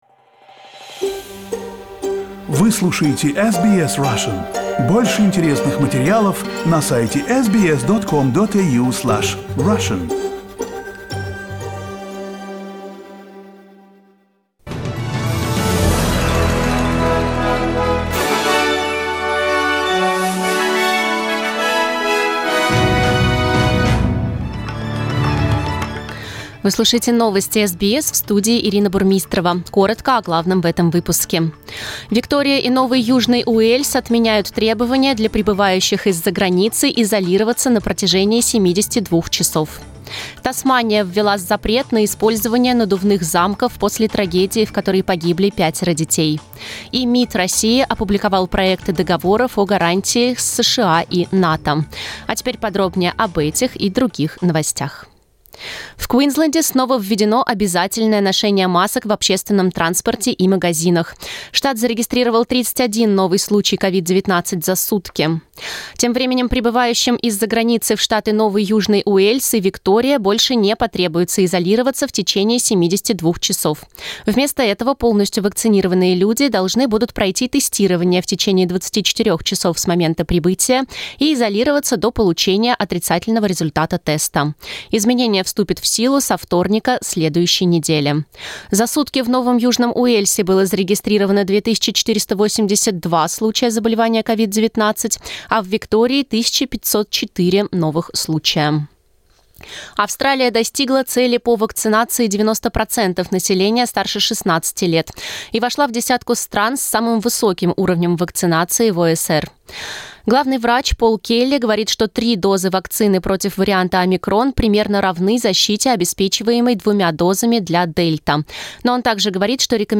SBS news in Russian - 18.12